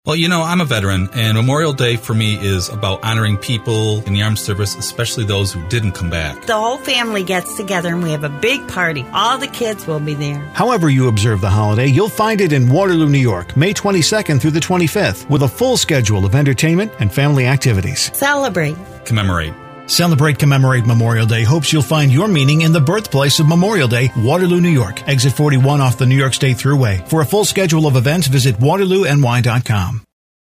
:30 PSA